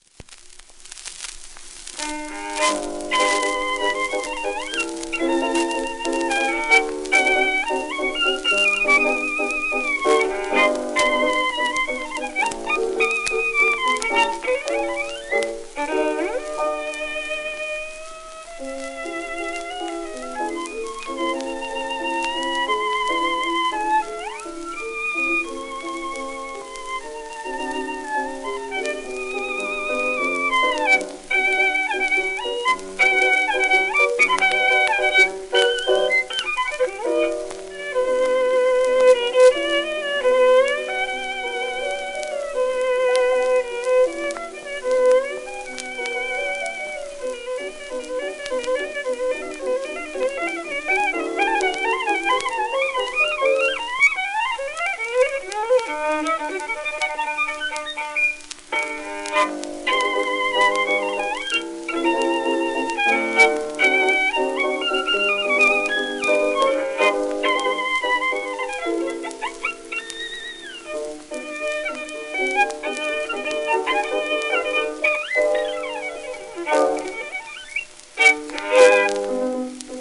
1922年頃録音、80rpm
旧 旧吹込みの略、電気録音以前の機械式録音盤（ラッパ吹込み）